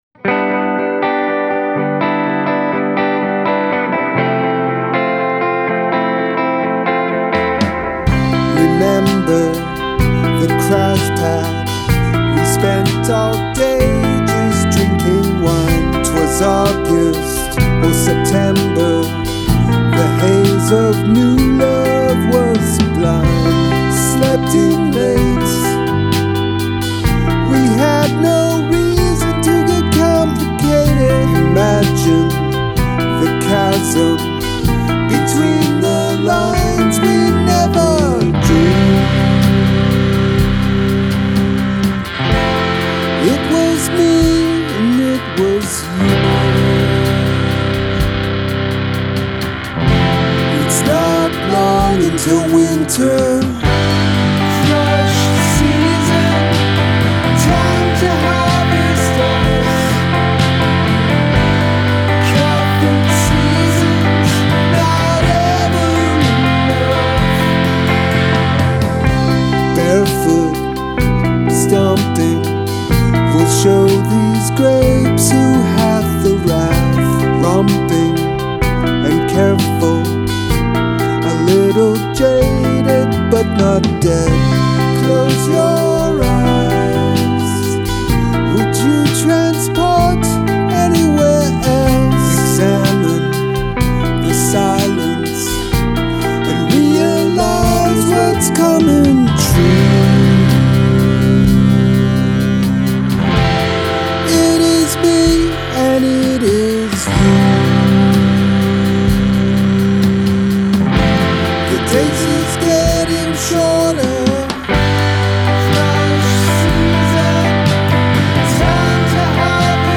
Use of Line Cliche
| C | C/B | C/ Bb | C/A | (x4)
Solo: over Short Verse and PreChorus
the long chords on the prechorus are great.